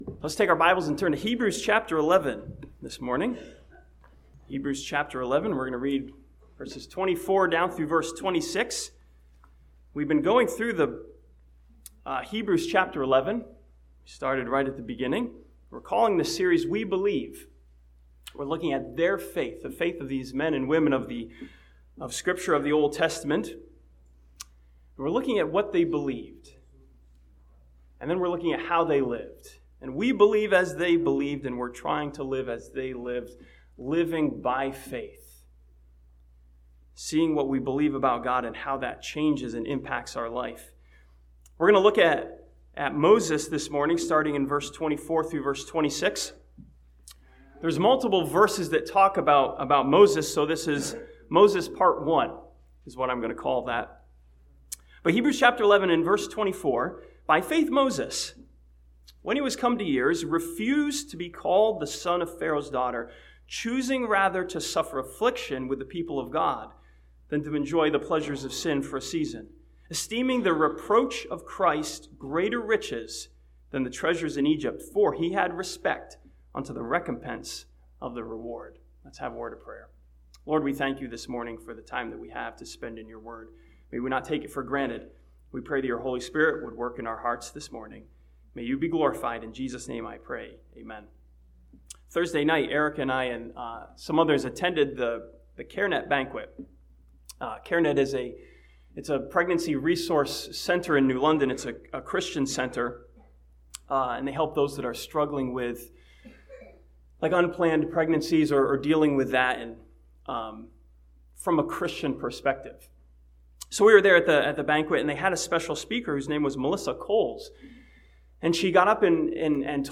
This sermon from Hebrews 11 focuses on Moses and his decision to identify with the people of God despite affliction and reproach.